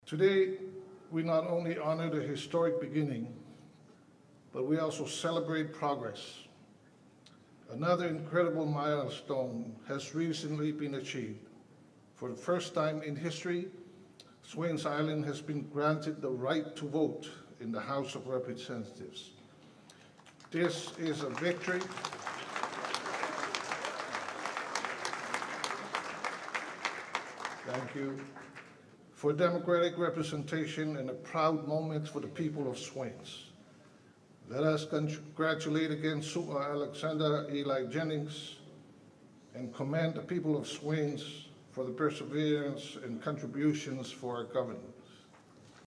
Governor Pulaalii, in his address, highlighted the fact that the Swains Island Representative now has full voting rights in the Fono.
governor-swians.mp3